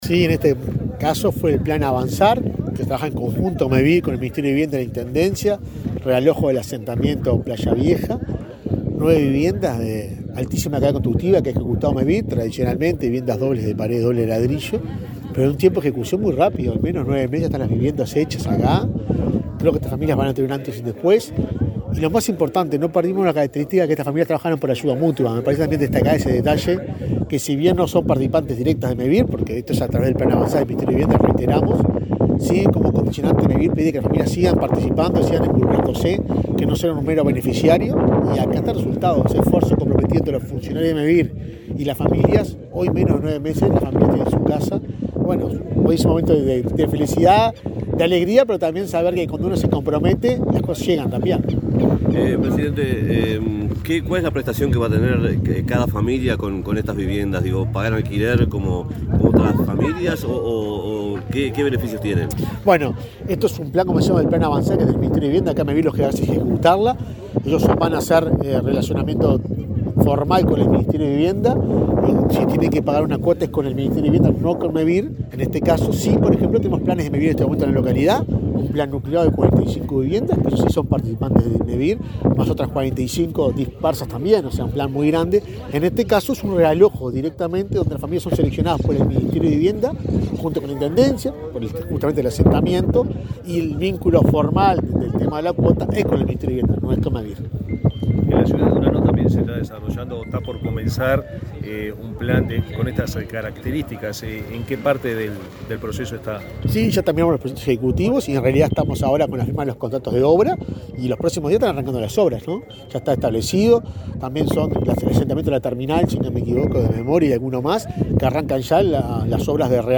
Declaraciones del presidente de Mevir, Juan Pablo Delgado
Declaraciones del presidente de Mevir, Juan Pablo Delgado 14/08/2023 Compartir Facebook X Copiar enlace WhatsApp LinkedIn El presidente de Mevir, Juan Pablo Delgado, dialogó con la prensa en Sarandí del Yí, departamento de Durazno, antes de participar en la inauguración de nueve viviendas ejecutadas por ese organismo, en el marco del plan Avanzar.